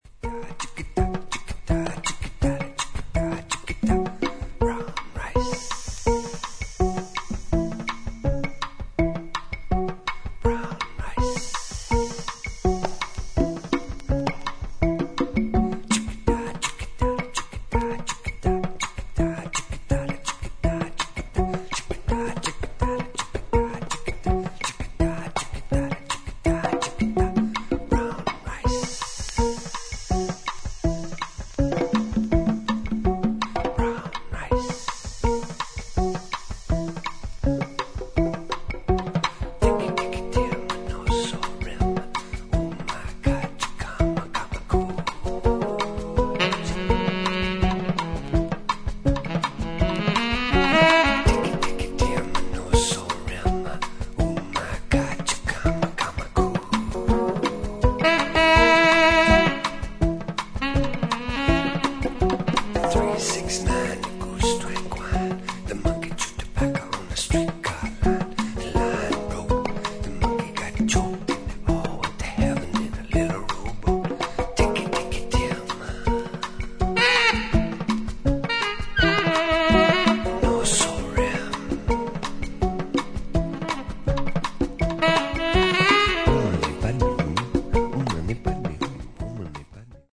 ロックの要素も取り入れた民族系ジャズ・グループ